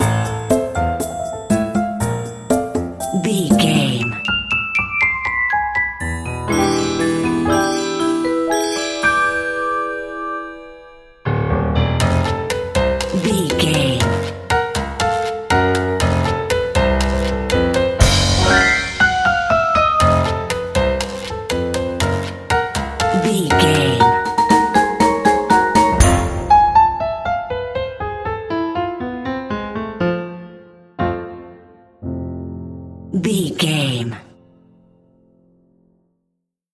Dorian
percussion
piano
silly
circus
goofy
comical
cheerful
perky
Light hearted
quirky